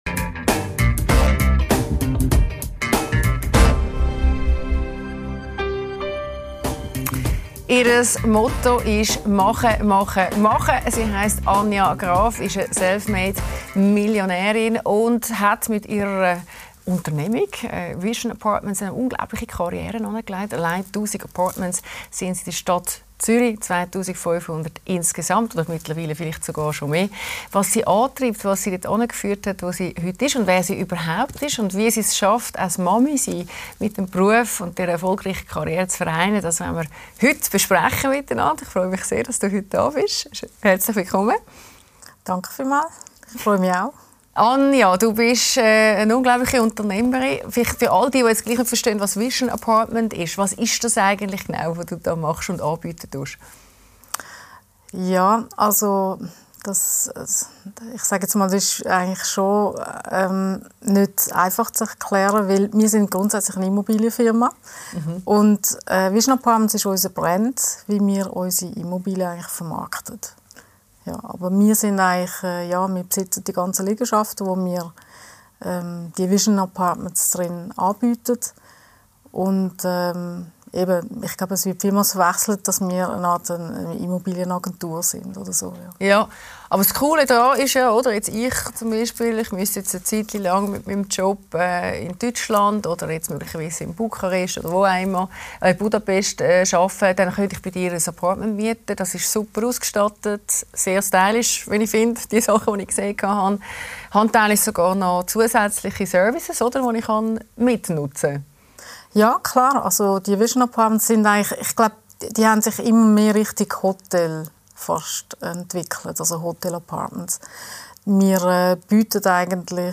Die Talkshow